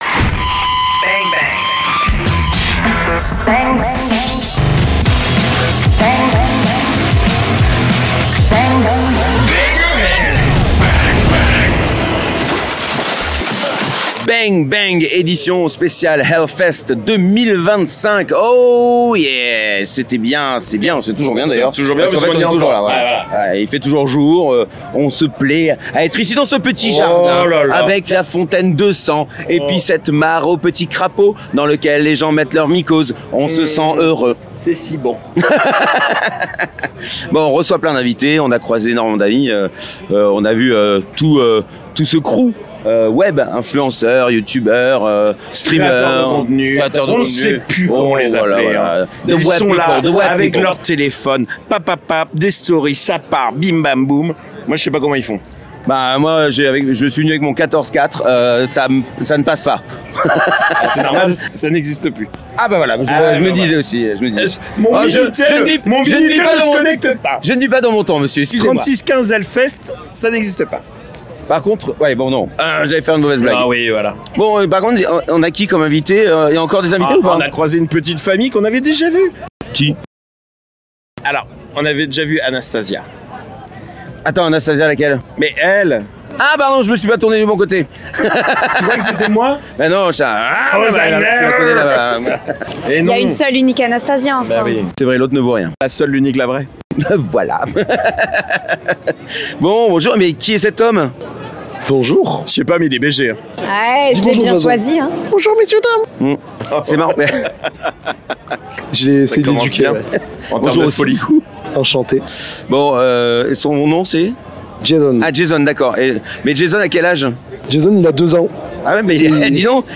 Voilà encore une édition de HELLFEST accomplie ! Et nous avons fait 23 interviews !